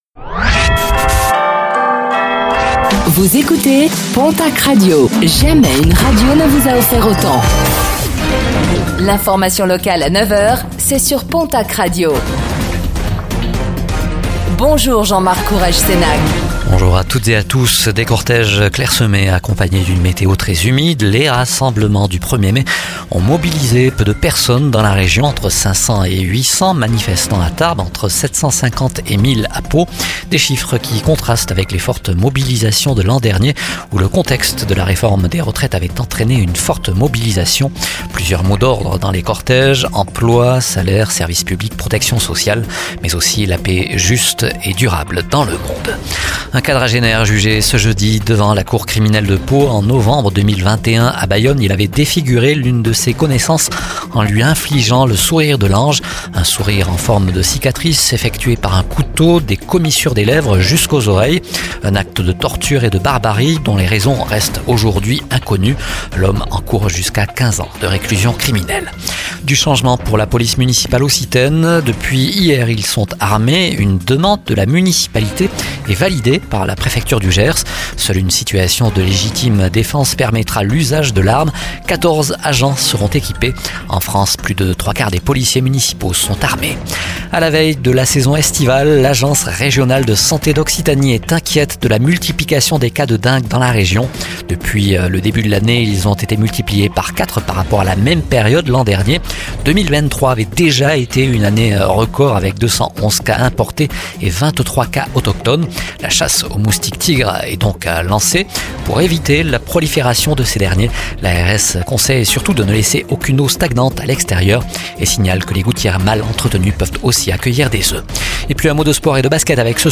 Réécoutez le flash d'information locale de ce jeudi 02 mai 2024